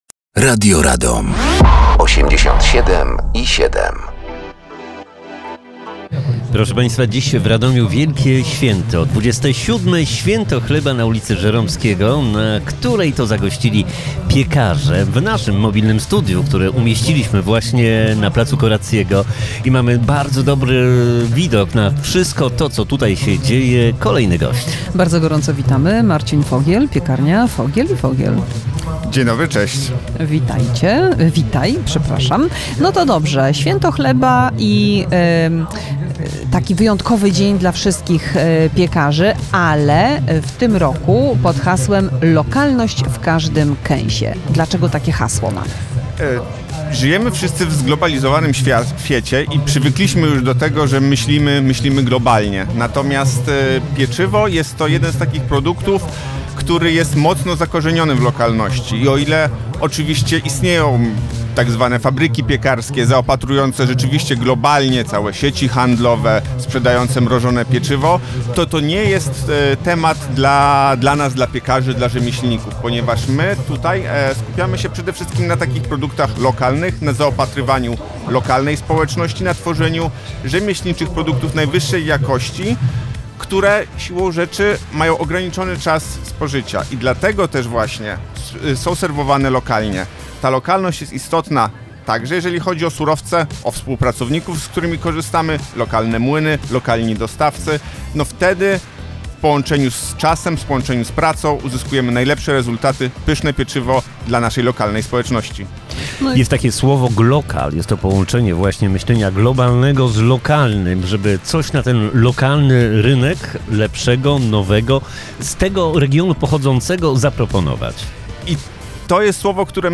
Mobilne Studio Radia Radom na Święcie Chleba 2025 w Radomiu.